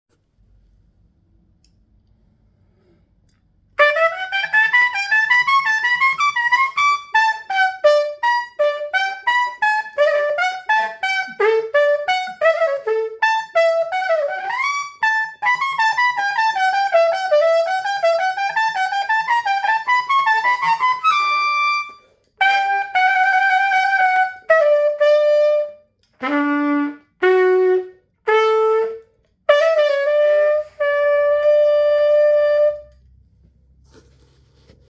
Abblassen Fanfare on piccolo trumpet.